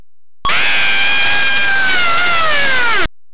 ↑音声付きよ！さぁ！ボリューム最大でGO!
gya-.mp3